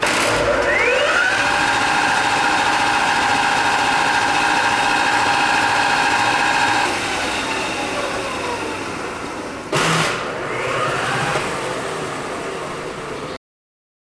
27-meat-grinder-field-recording.mp3